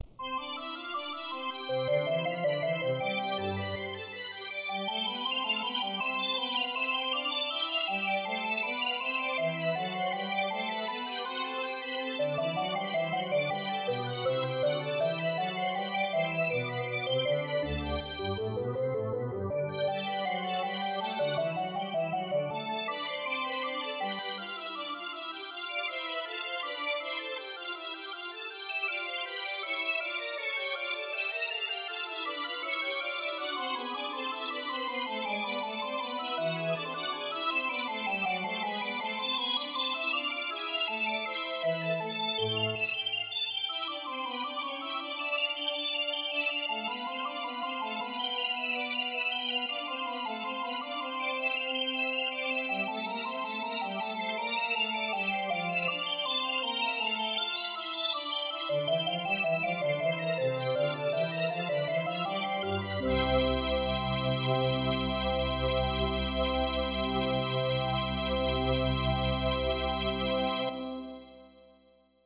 Le principe est que j'étire les octaves d'un 7ème de comma, et tout est étiré de façon homothétique.